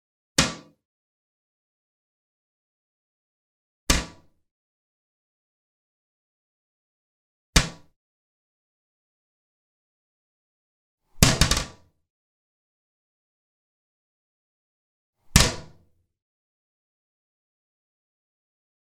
Toilet Lid Lower Down Sound
household
Toilet Lid Lower Down